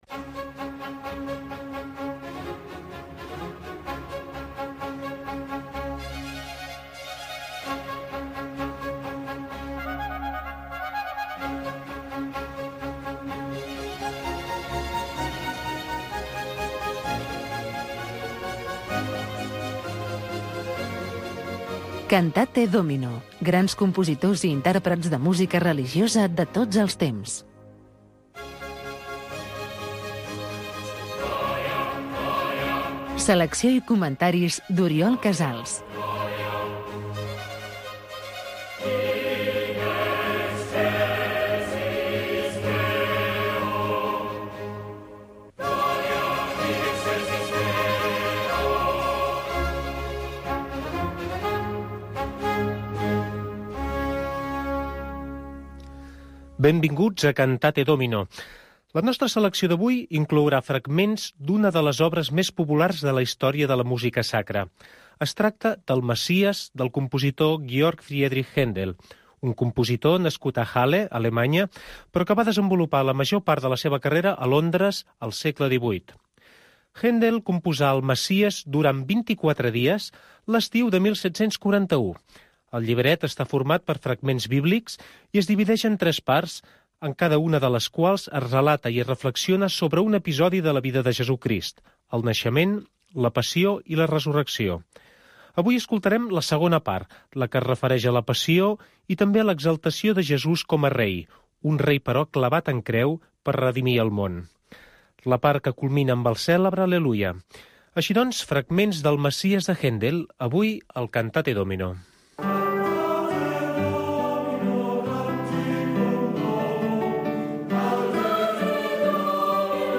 Programa de música clàssica religiosa.